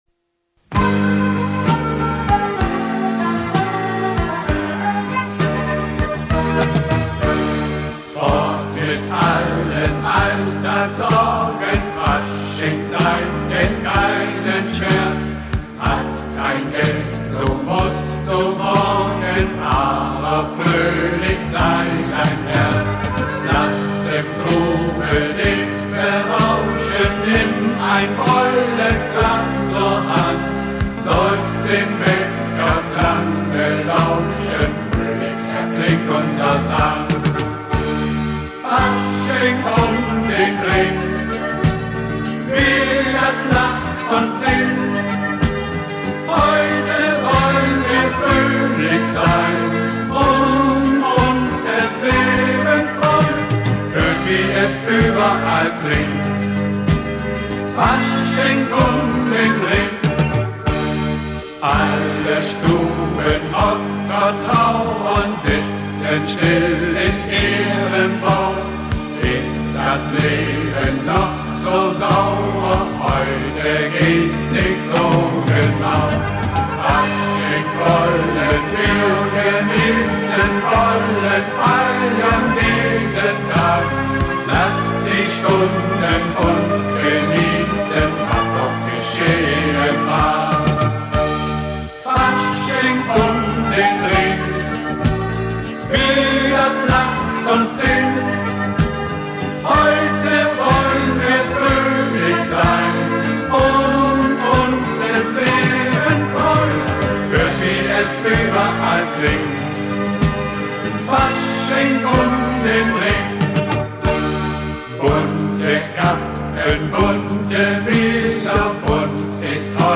Zum Mitsingen:
faschingsschlager